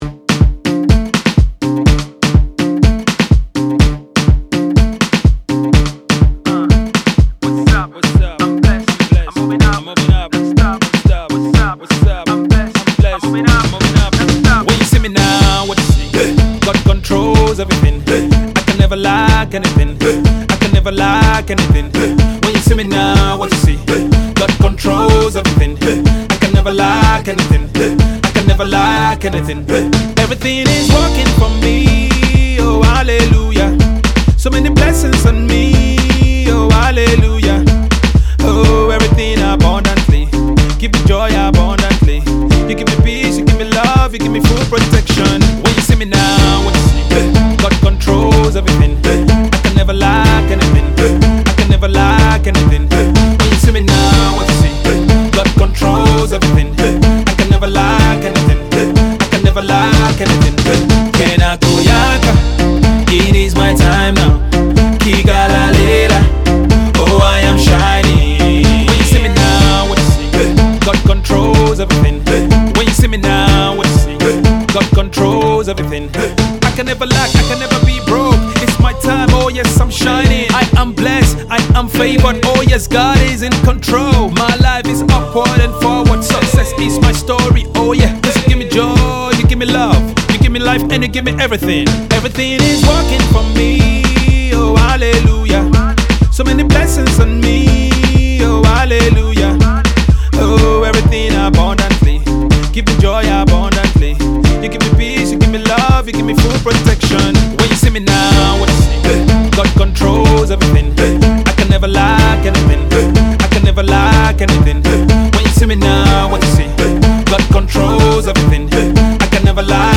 Gospel MUSICIAN
a fresh danceable tune